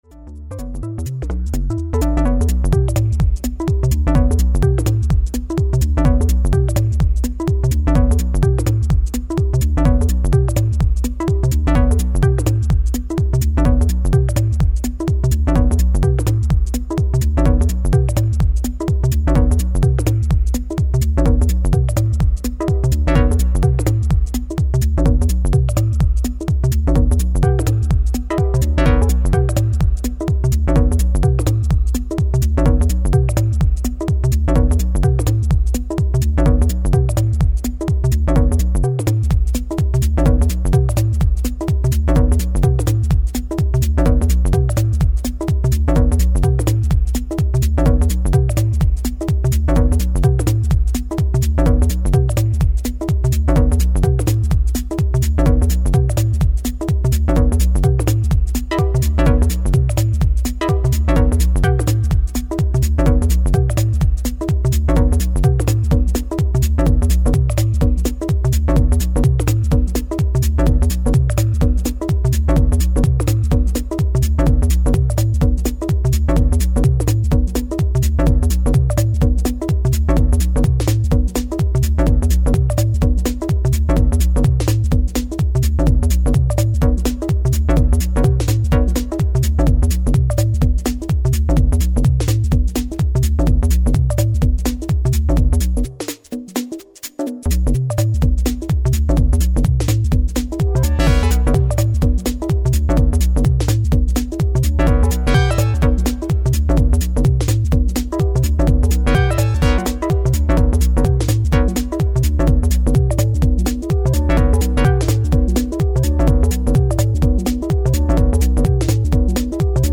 live
house and techno